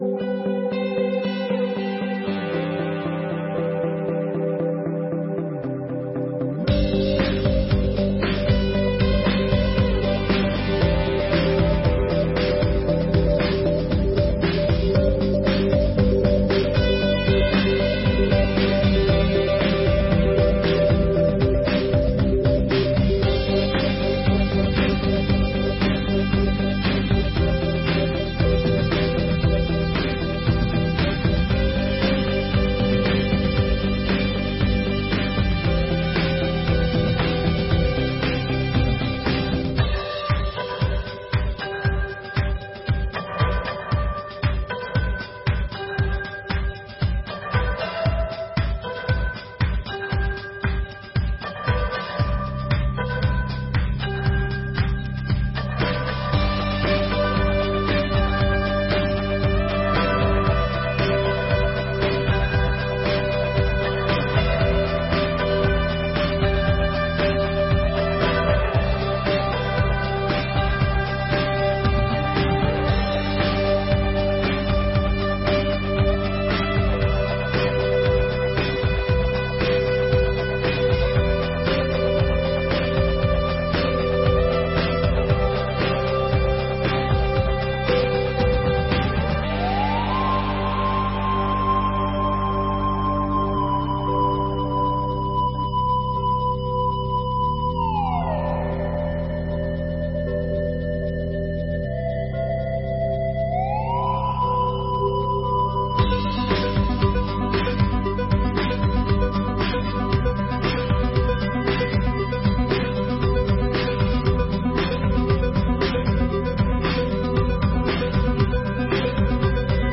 Sessões Solenes de 2024